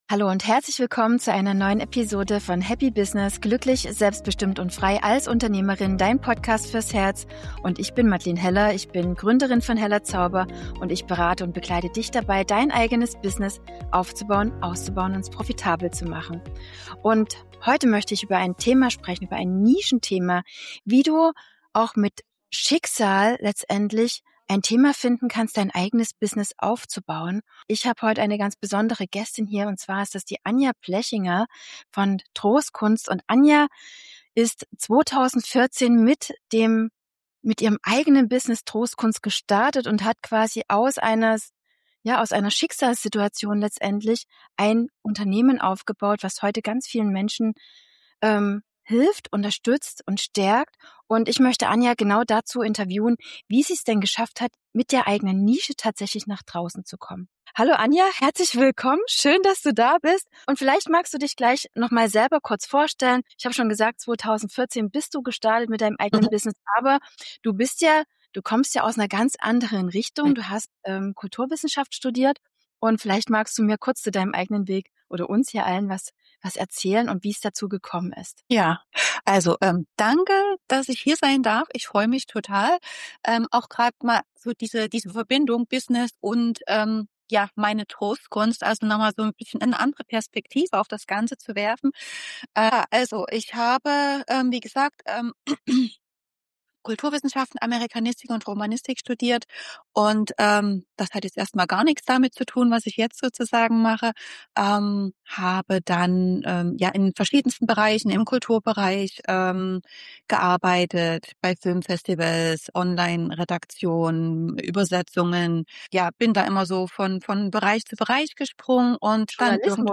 Deine Nische liegt in dir: Vom Schicksal zur Berufung (Interview